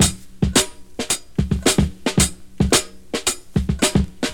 • 110 Bpm HQ Drum Groove C Key.wav
Free drum groove - kick tuned to the C note. Loudest frequency: 3985Hz
110-bpm-hq-drum-groove-c-key-Mts.wav